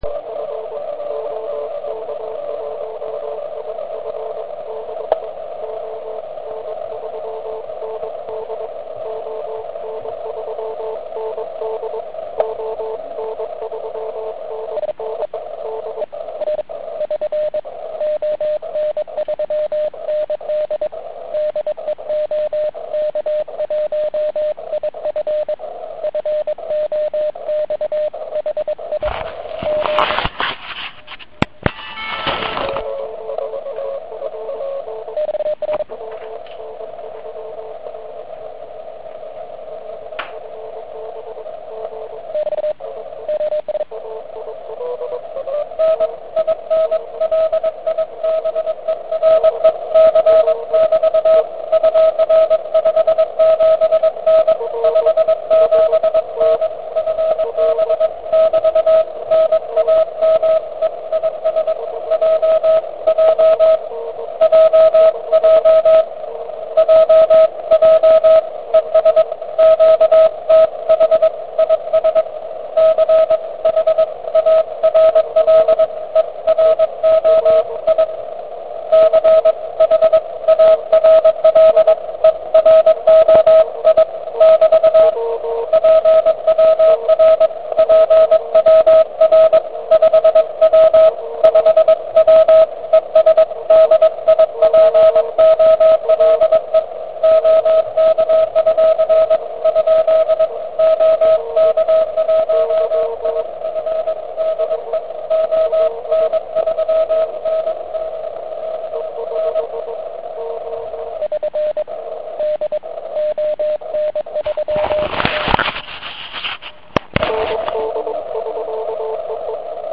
Ale 80m band byl ještě otevřen tak pro vzdálenosti přes 500km (Fkr byl někde přes 2,2 MHz).
Ihned po výzvě jsem slyšel malinkatý a velice slabý pile up.